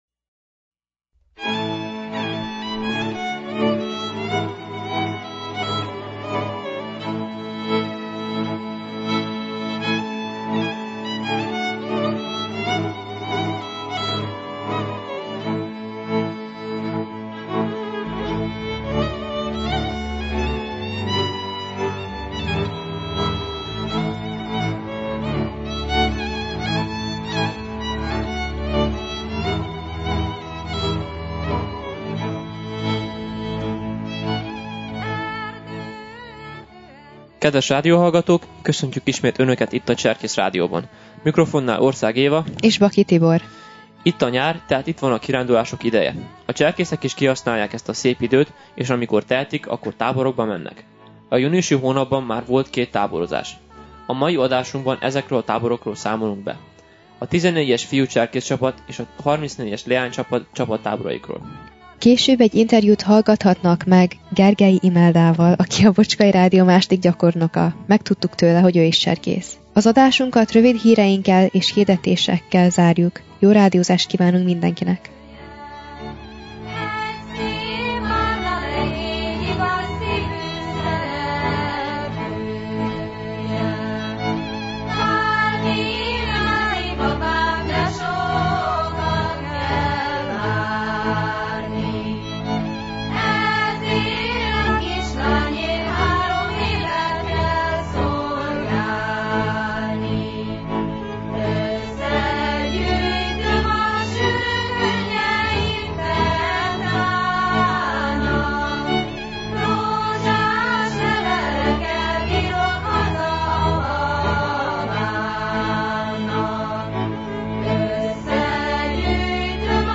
A tartalomból: Beszámoló a 14-es fiú cserkészcsapat és 34-es leánycsapat csapat táborairól. (interjú